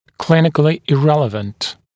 [‘klɪnɪklɪ ɪ’reləvənt][‘клиникли и’рэлэвэнт]не имеющий значения в клинической практике